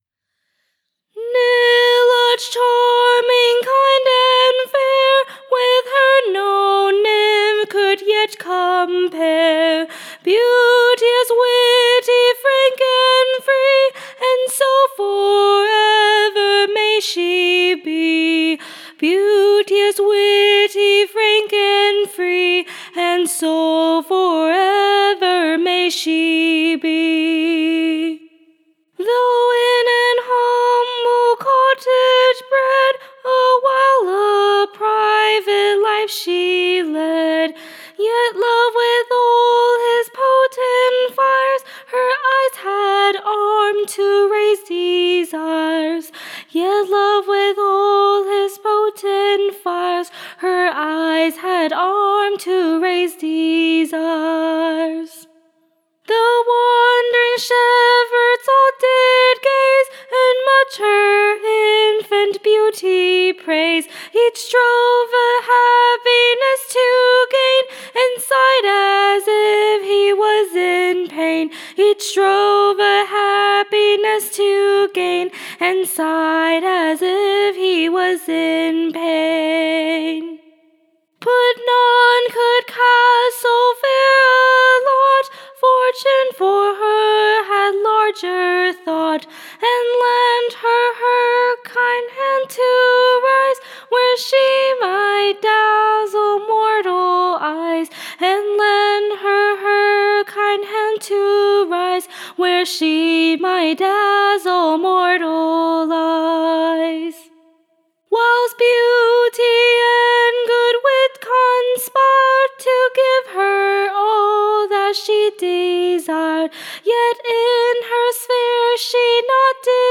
/ A Pleasant New SONC. Tune Imprint To the Tune of, Joy to the Bridegroom.